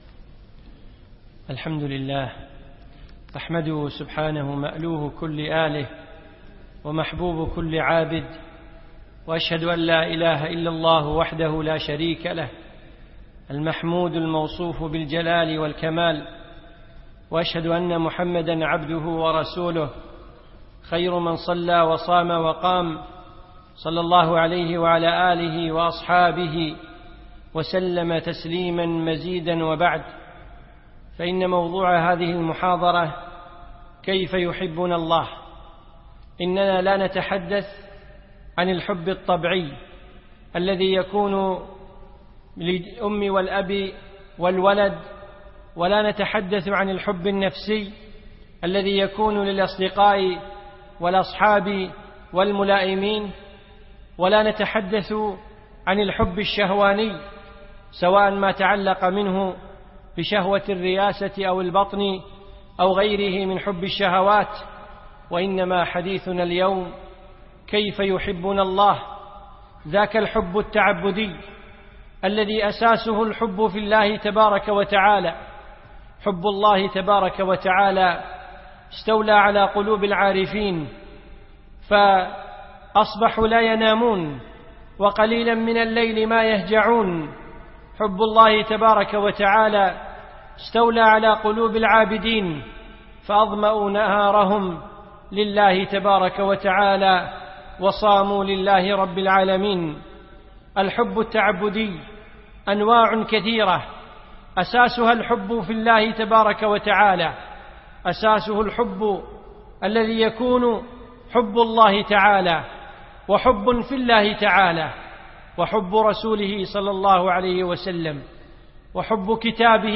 من دروس الشيخ في دولة الإمارات في رمضان 1436